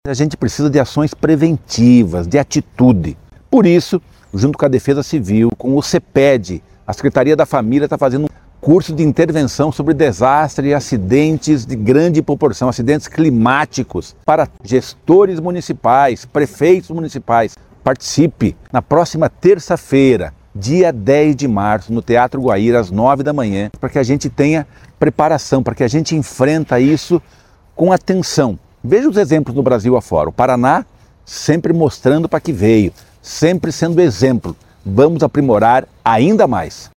Sonora do secretário do Desenvolvimento Social e Família, Rogério Carboni, sobre o curso de Intervenção em Desastres voltado a gestores